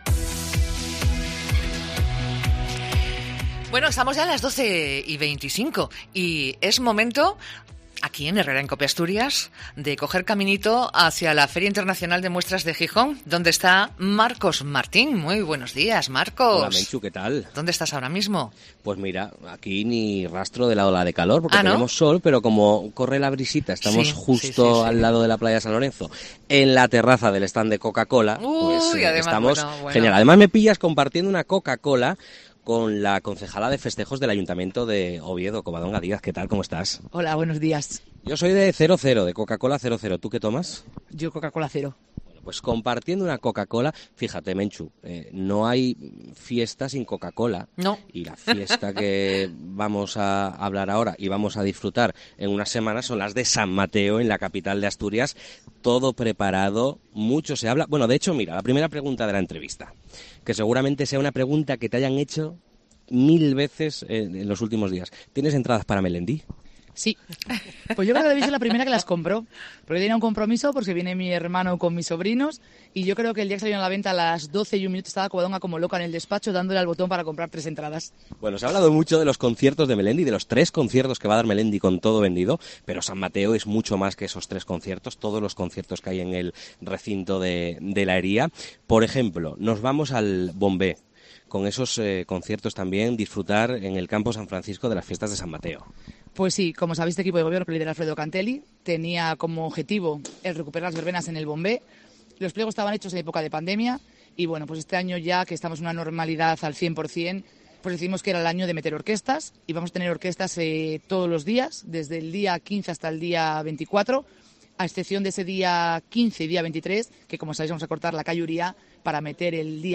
FIDMA 2023: entrevista a Covadonga Díaz, concejala de Festejos de Oviedo
La concejala de Festejos del Ayuntamiento de Oviedo, Covadonga Díaz, ha participado este martes en el especial que COPE emite desde la Feria Internacional de Muestras de Asturias. En la terraza del stand de Coca-Cola, la edil ha explicado alguna de las actividades que tendrán lugar en la capital asturiana durante las fiestas de San Mateo.